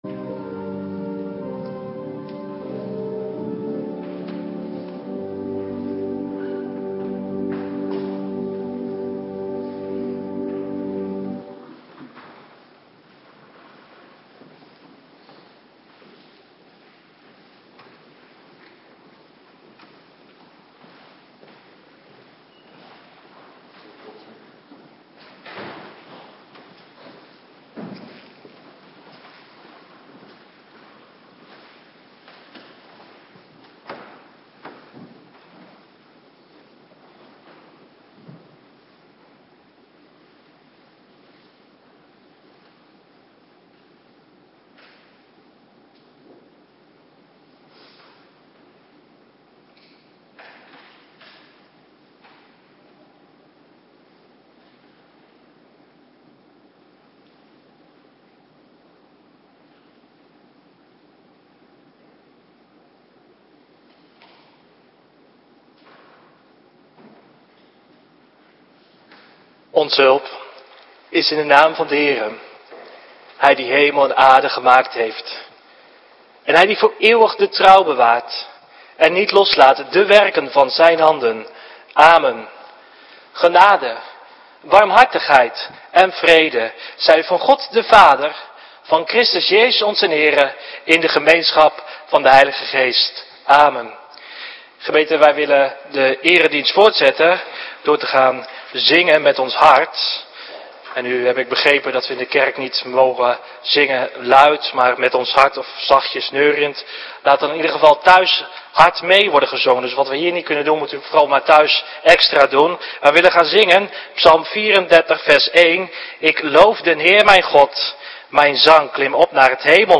Avonddienst - Cluster 2
Locatie: Hervormde Gemeente Waarder